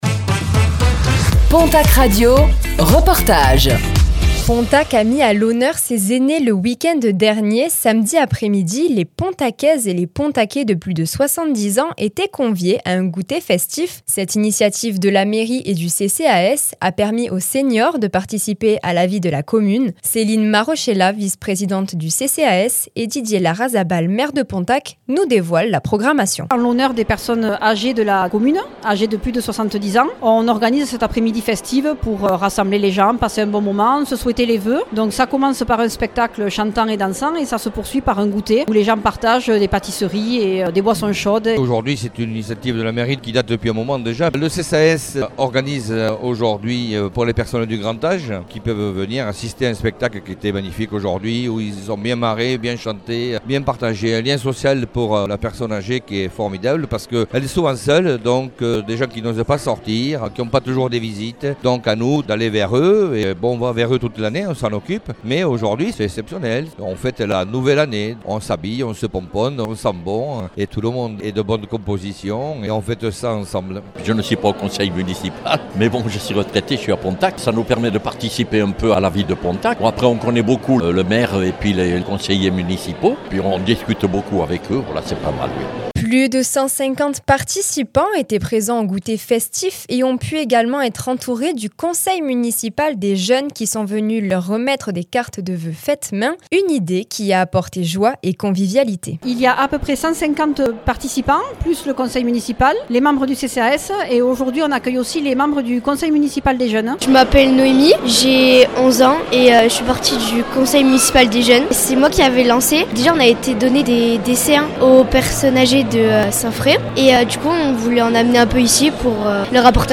À Pontacq, un goûter festif pour célébrer les aînés - Reportage du 20 janvier 2026